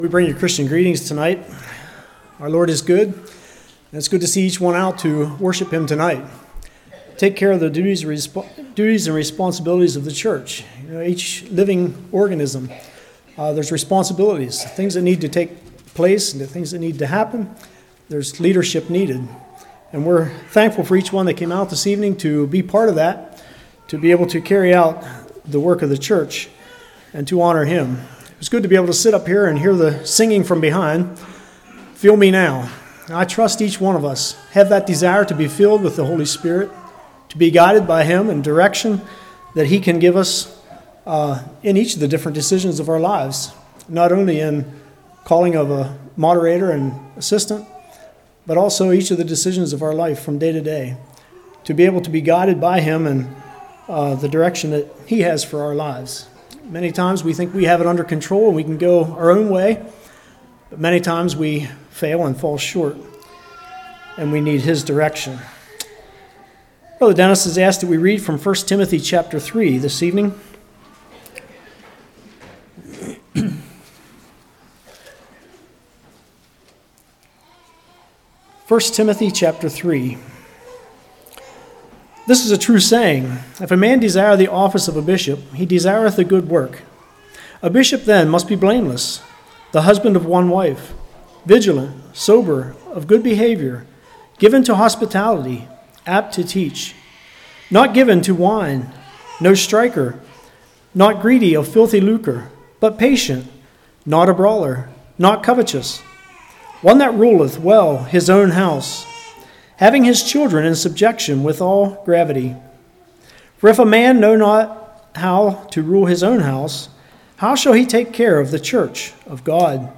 1 Timothy 3 Service Type: Church Elections Treat the church as family members.